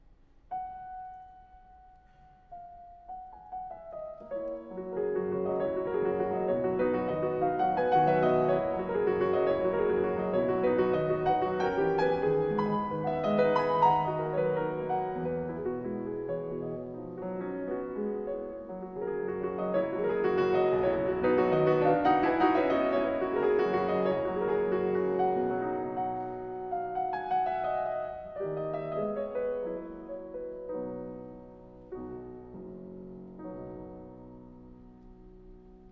My favourite is Frederic Chopin’s ‘The Dragonfly’, Prelude in B Major, Op. 28 No.11 , performed here by Seong-Jin Cho: